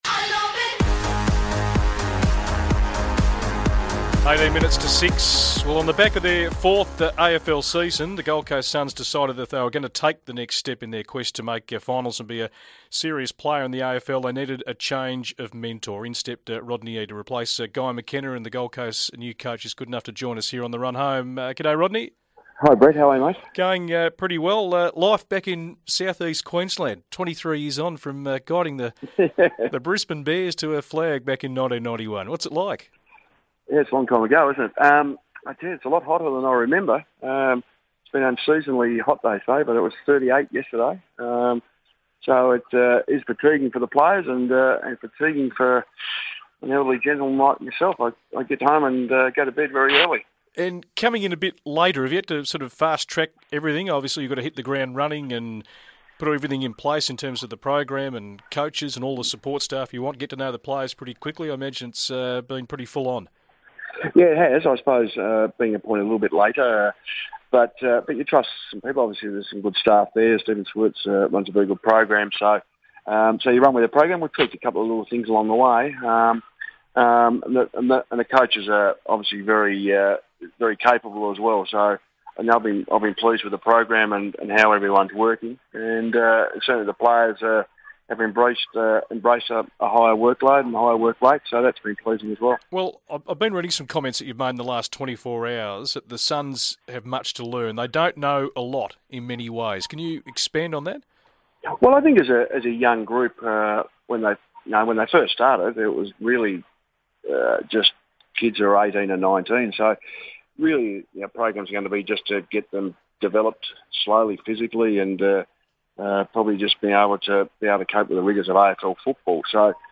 Gold Coast coach Rodney Eade speaks to The Run Home about the pre-season at the SUNS.